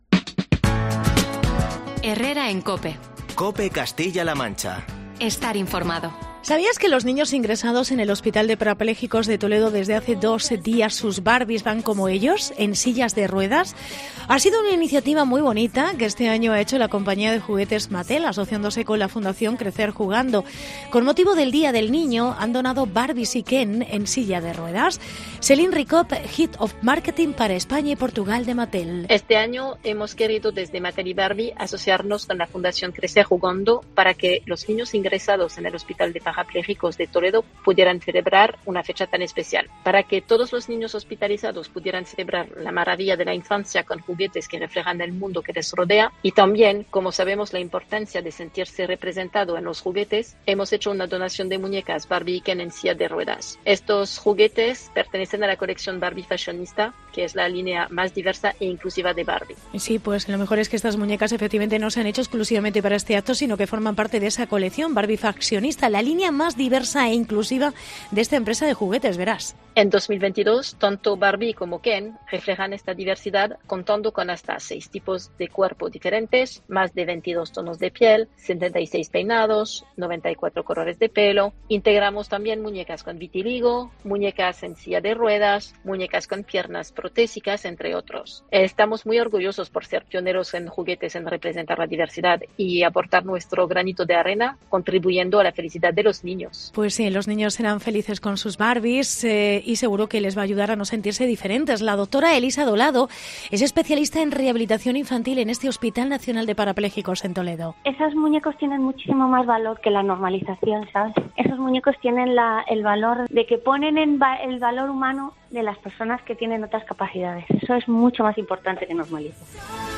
Reportaje Barbies en el Hospital Nacional de Parapléjicos